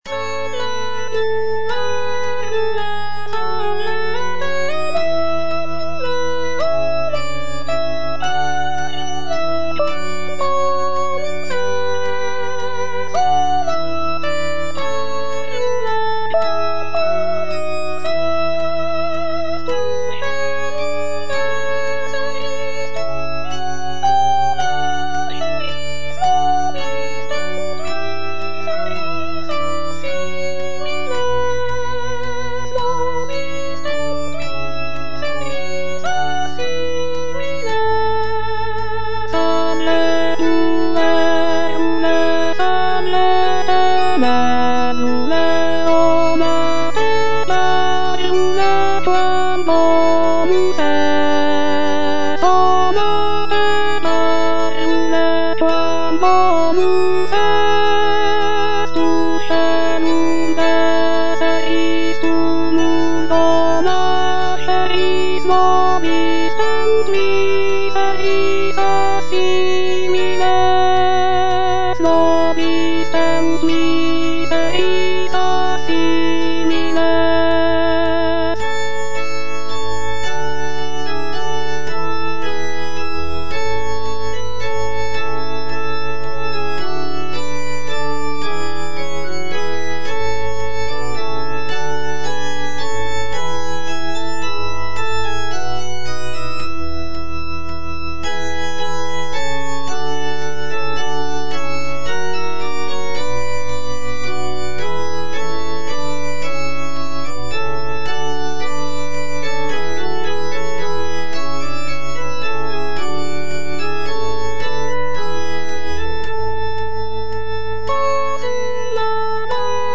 M.A. CHARPENTIER - SALVE PUERULE Alto (Voice with metronome) Ads stop: auto-stop
It is a Christmas motet, written in Latin, and is dedicated to the Virgin Mary. The piece showcases Charpentier's mastery of vocal writing with its delicate melodies and rich harmonies.